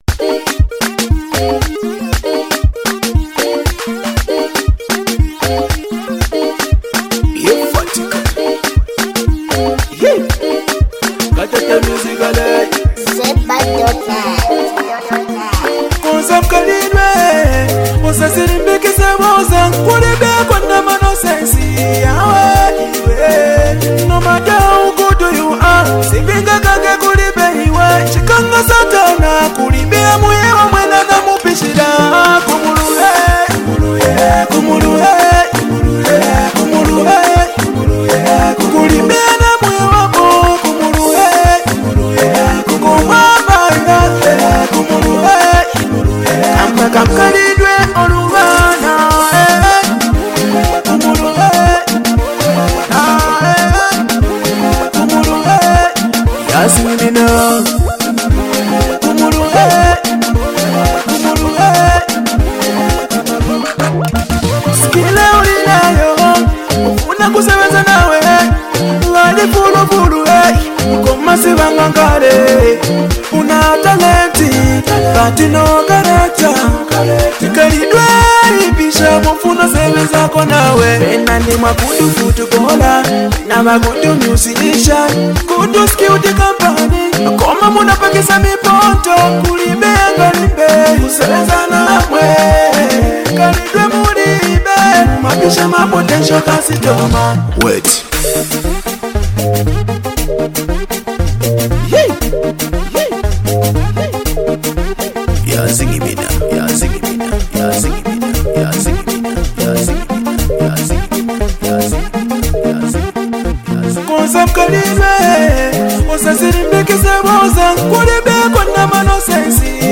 upbeat music